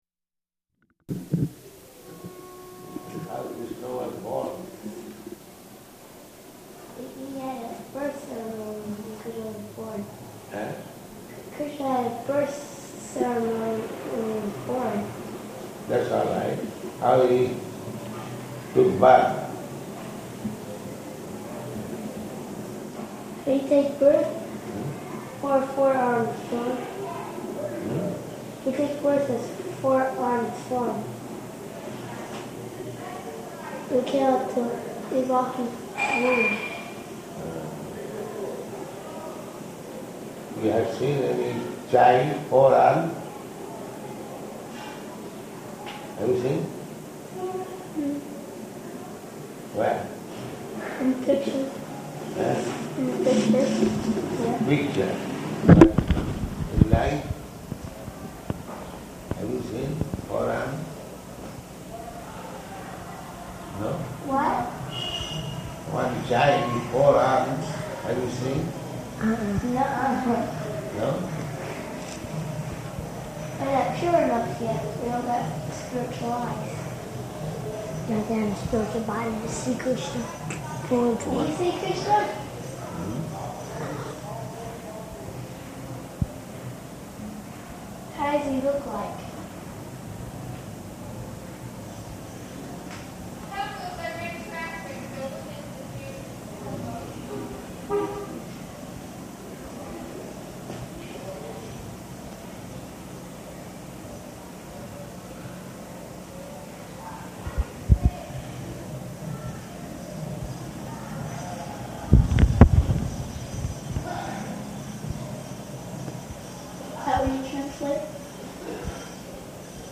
Room Conversation
Room Conversation --:-- --:-- Type: Conversation Dated: July 4th 1972 Location: New York Audio file: 720704R1.NY.mp3 Prabhupāda: How Kṛṣṇa was born?